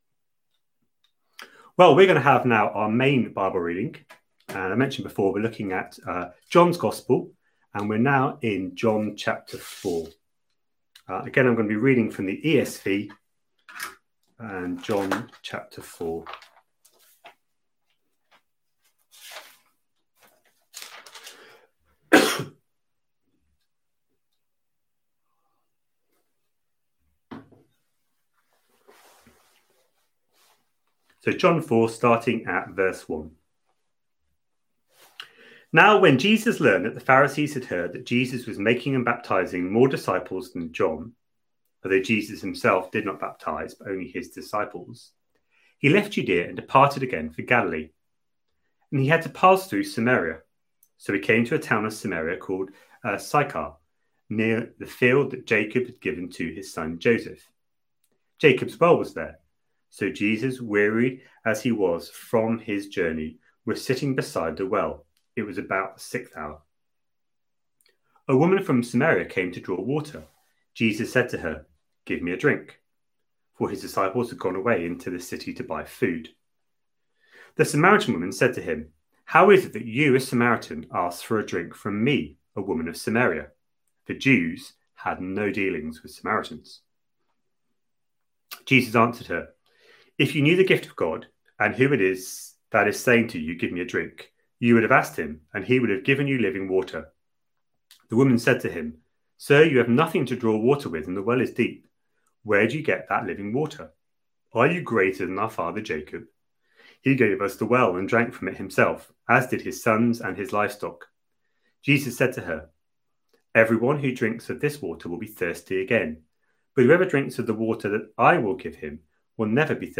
A sermon preached on 31st May, 2020, as part of our John: The Father's Son series.